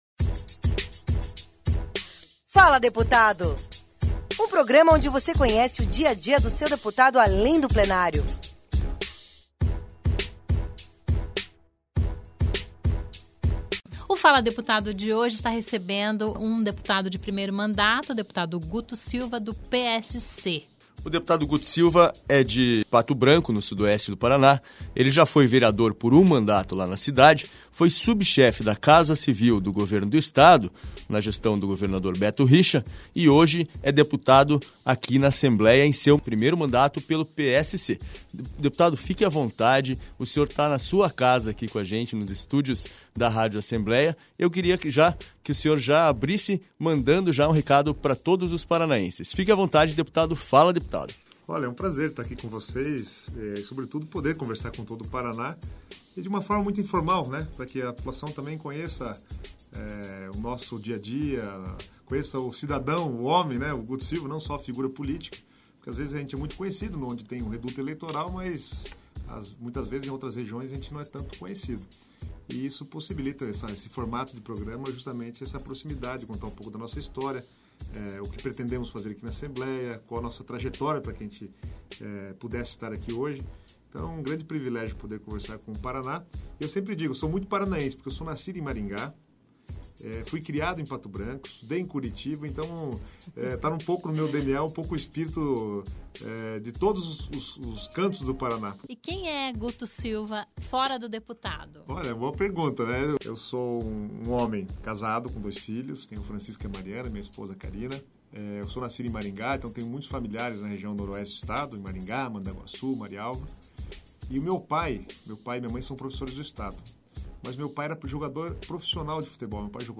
Ele é o entrevistado desta semana do Fala Deputado, programa da Rádio Assembleia No Fala Deputado desta semana, você vai conhecer...